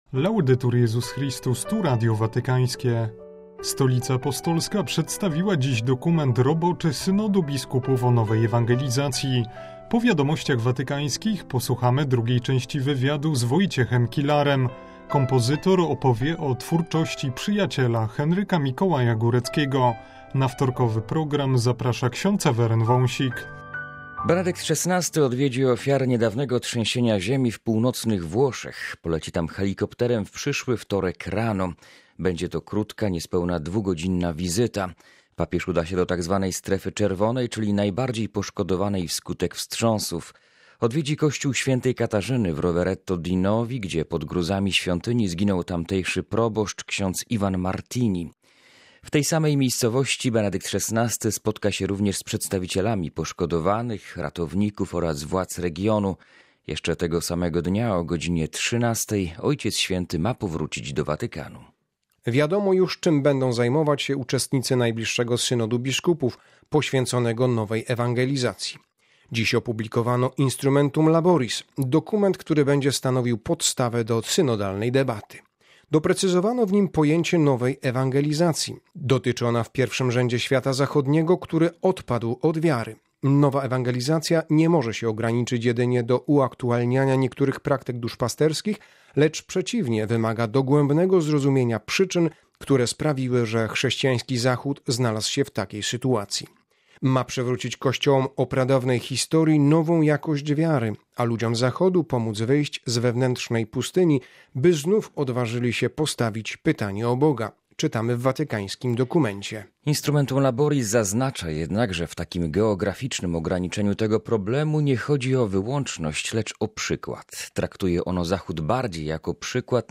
W programie: omówienie dokumentu roboczego Synodu Biskupów o nowej ewangelizacji oraz druga część wywiadu z Wojciechem Kilarem o muzyce sakralnej Henryka Mikołaja Góreckiego.